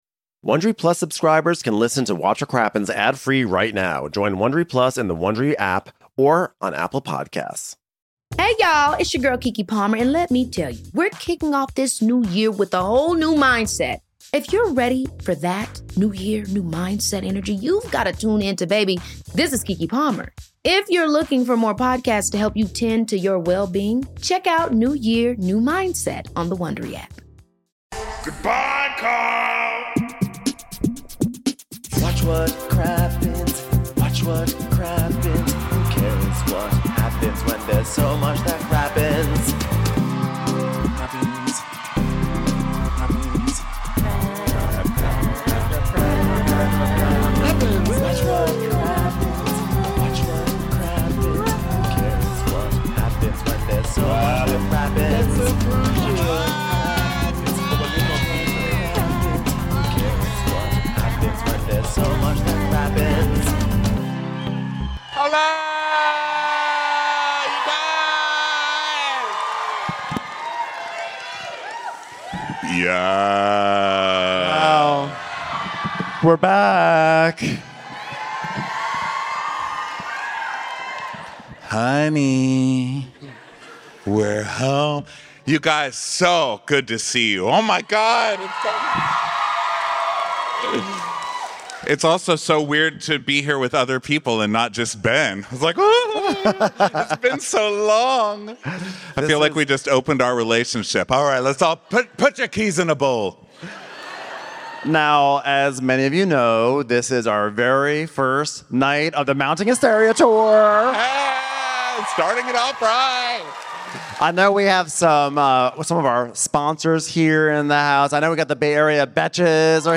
#2702 RHOSLC S5E17: Shaky Alibaba - Live from SF
Thanks for a great show, SF!